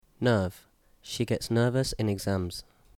1 Nerve nɜːv